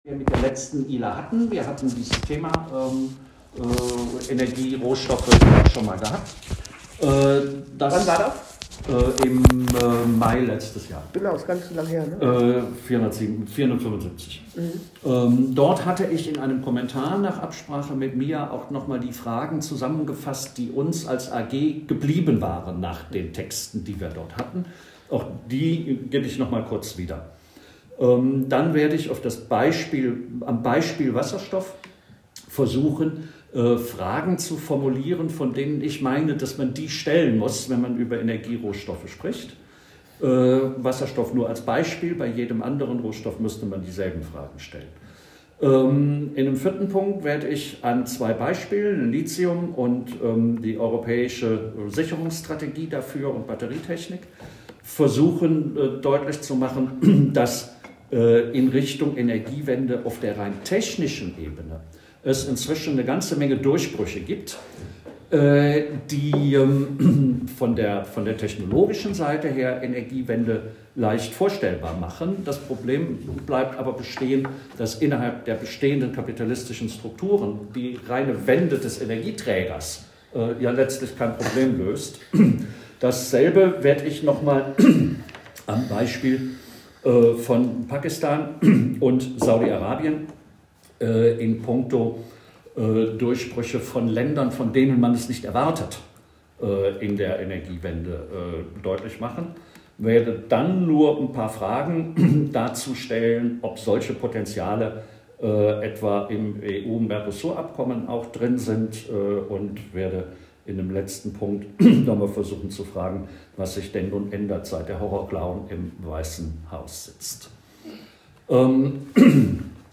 Rohstoffenergiehunger, Input beim ila-Zukunftswochenende am 16.2.2025 zur Vorbereitung der ila 484, April 2025